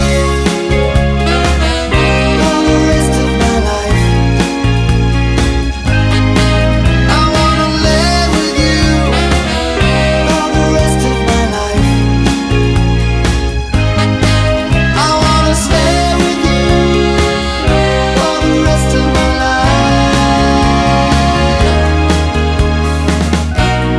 No Saxophone Solo Pop (1970s) 3:05 Buy £1.50